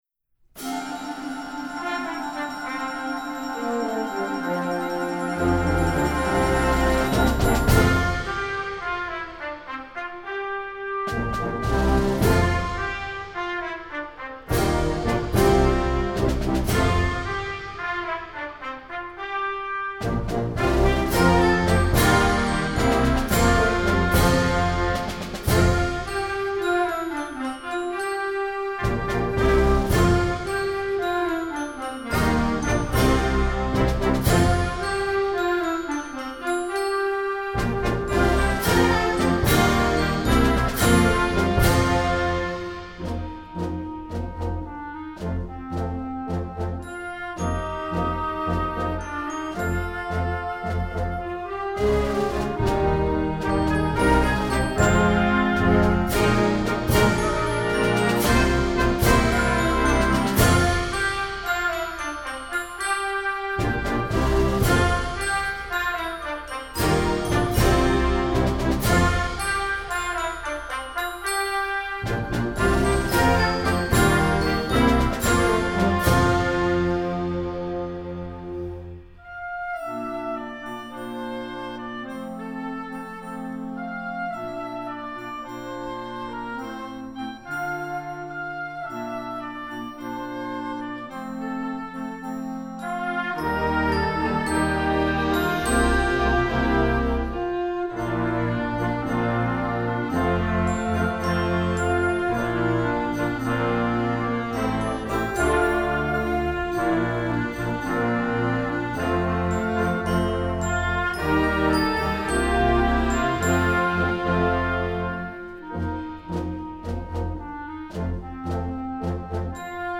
Gattung: Ouvertüre für Jugendblasorchester
Besetzung: Blasorchester